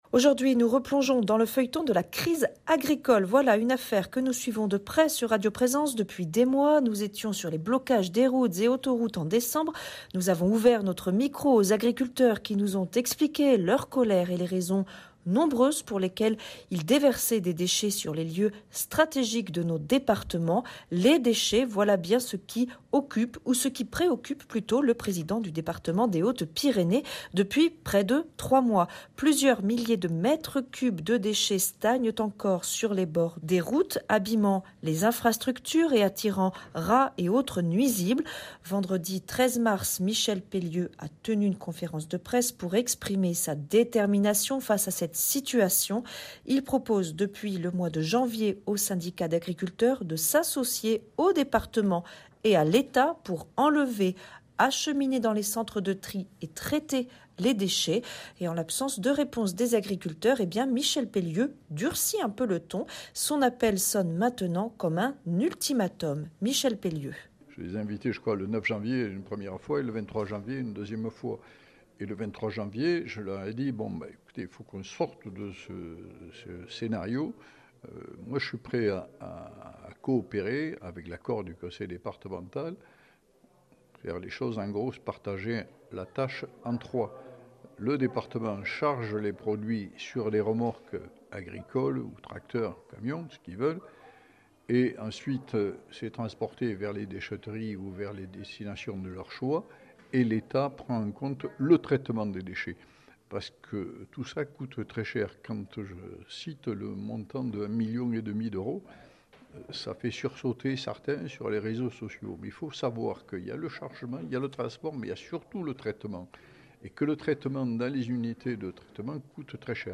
mardi 17 mars 2026 Interview et reportage Durée 10 min